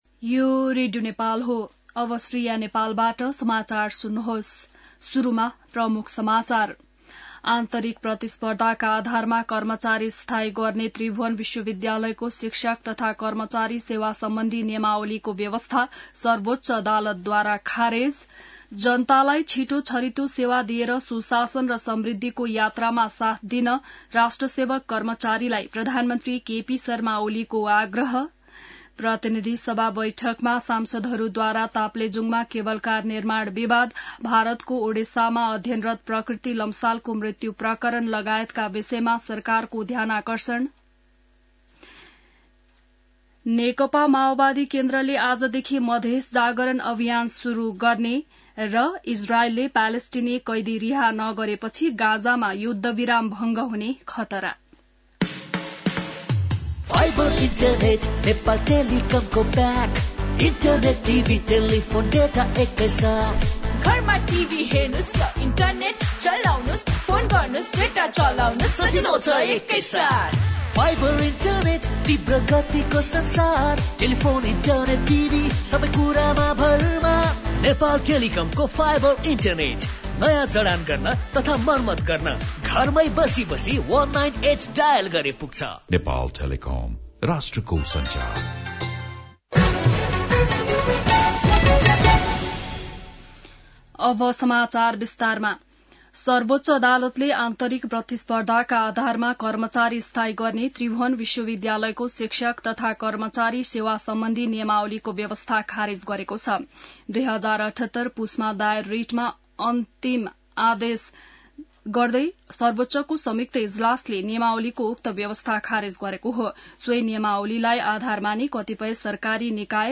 An online outlet of Nepal's national radio broadcaster
बिहान ७ बजेको नेपाली समाचार : १३ फागुन , २०८१